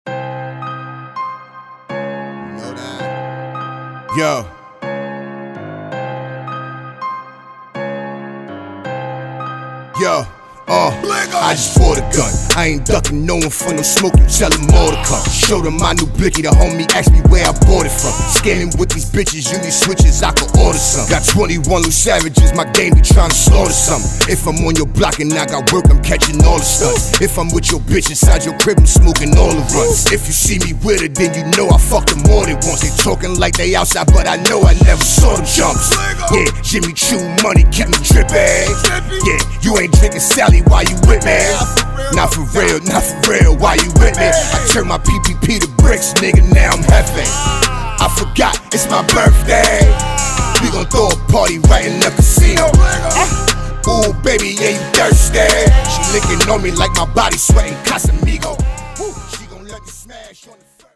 Trap
One-Shot Samples